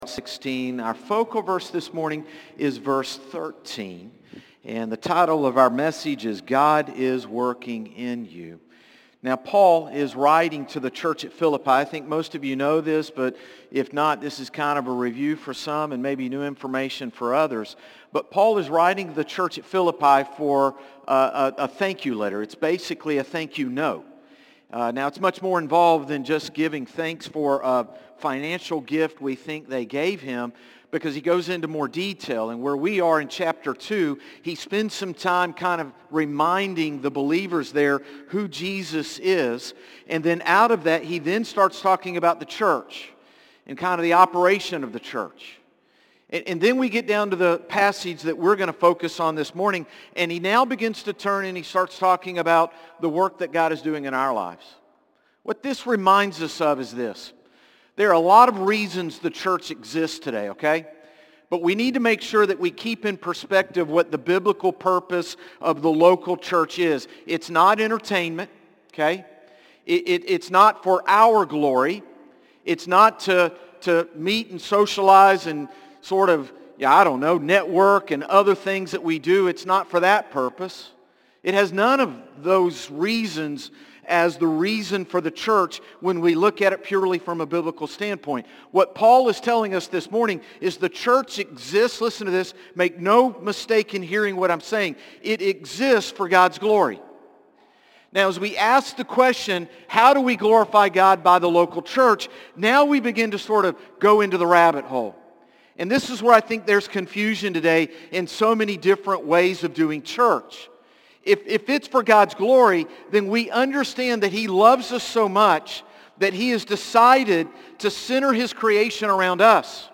Sermons - Concord Baptist Church
Morning-Service-7-13-25.mp3